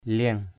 /lie̞ŋ/ 1. (đg.) trổ bông = volutes. flowering, draw flower or frame on an object. liéng batuw kut _l`$ bt~| k~T trổ bông đá kút = volutes flammées des stèles...